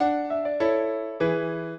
piano
minuet6-2.wav